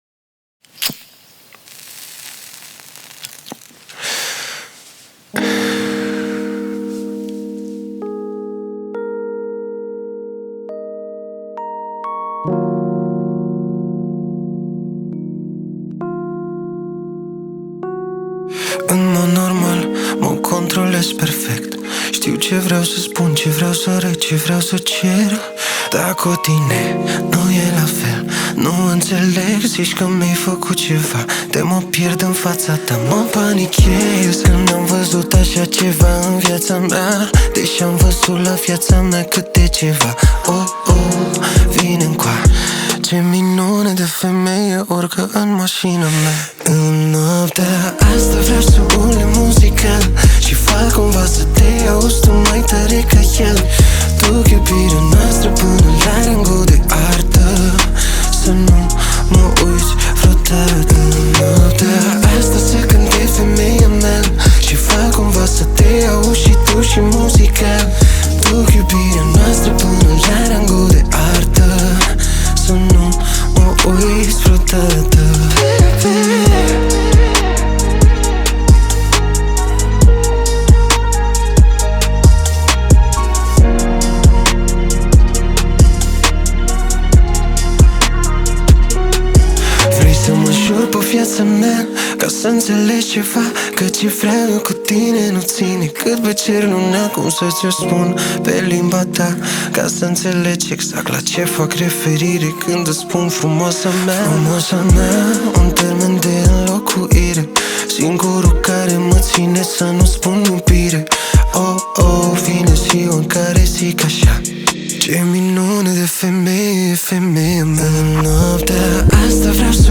o piesă care îmbină emoție și ritm, ideală pentru relaxare
Muzica Romaneasca